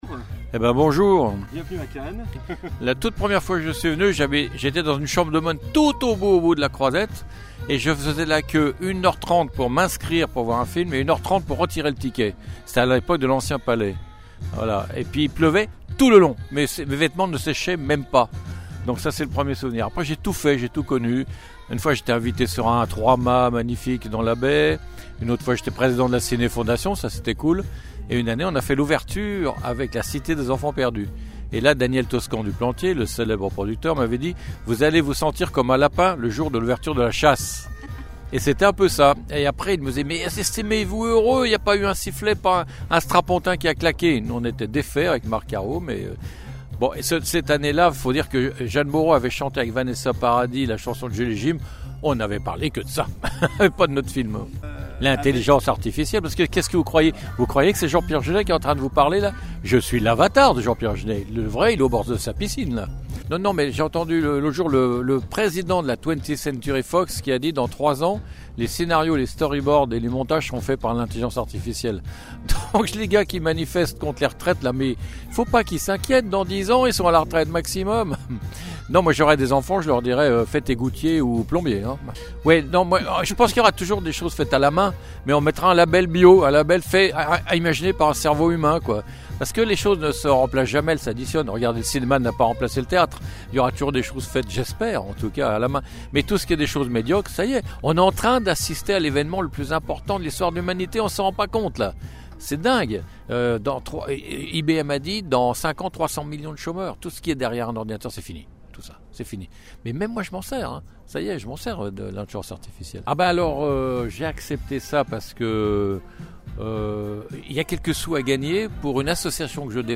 Lors du Festival de Cannes 2023, Jean-Pierre Jeunet, réalisateur emblématique, a accordé une interview exclusive à LA RADIO DU CINÉMA, où il a partagé ses souvenirs, ses réflexions sur l'avenir du cinéma et ses projets actuels.